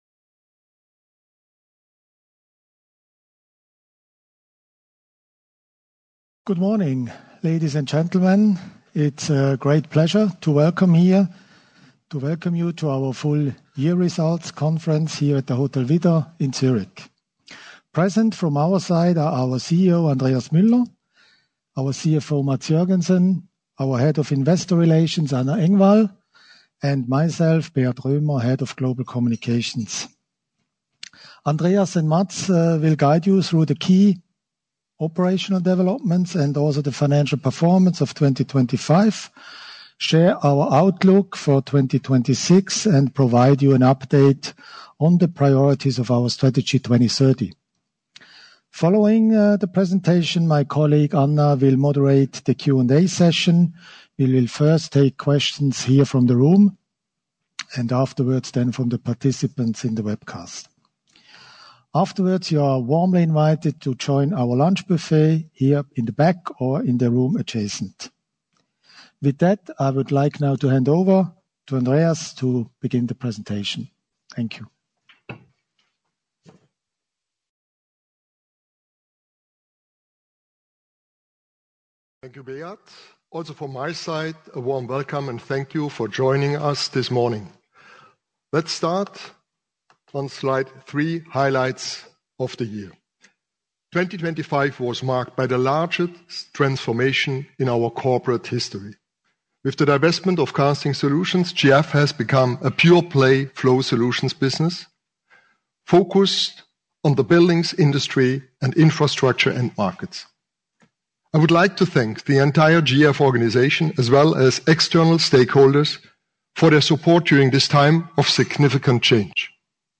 audio-webcast-analyst-and-media-conference-2026-en.mp3